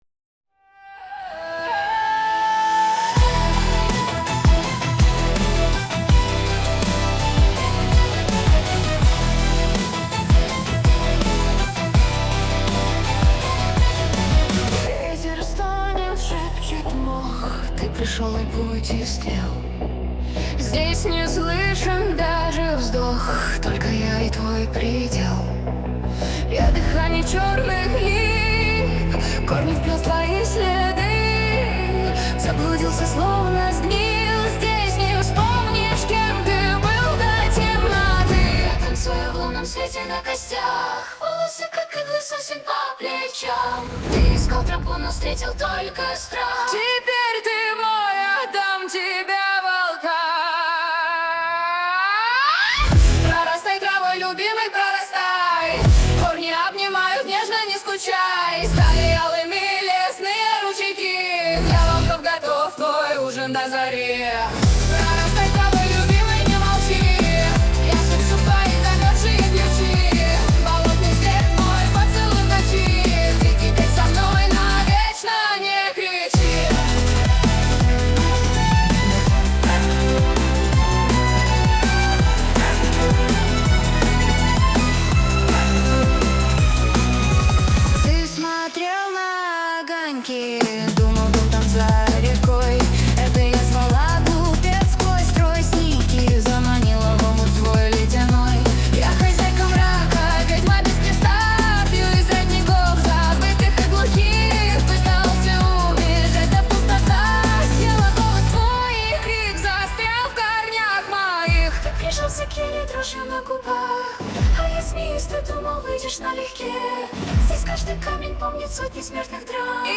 неофолк, шаманизм, dnb